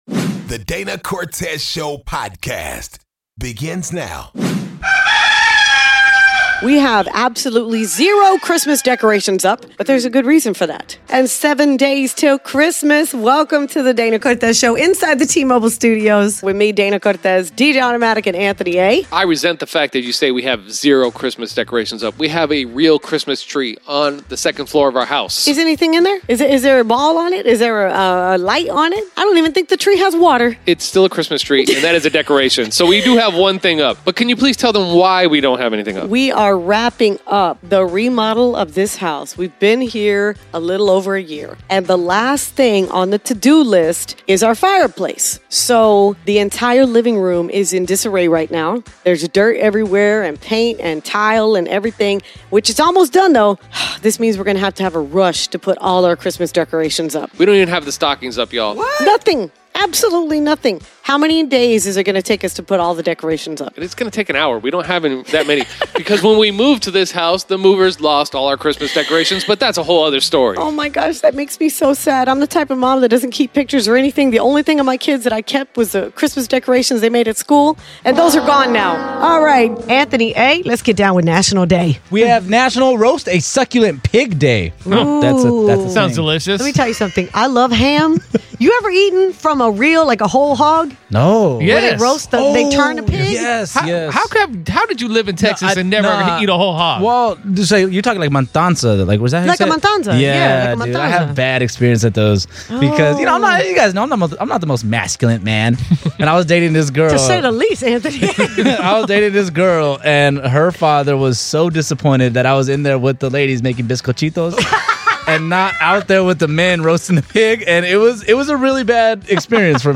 Plus DCS plays "Who Lying" w/ a listener and talks about a man who pushed his wife from a cliff and tried to say it was an accident.